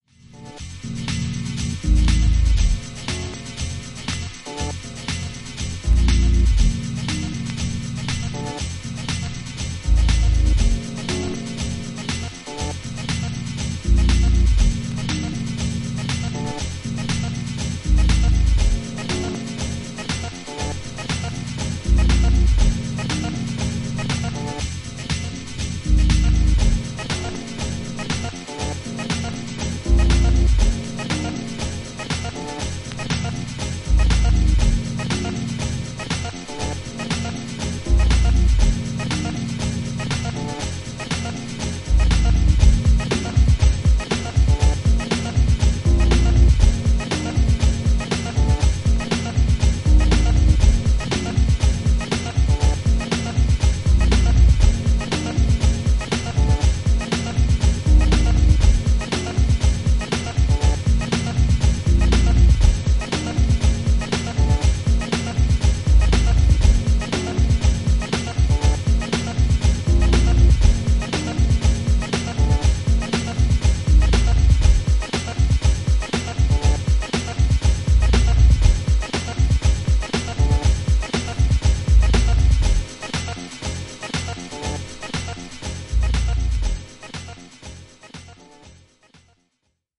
ハウスとブレイクビーツの中間を行くようなエレクトロニック・ジャズ・ファンク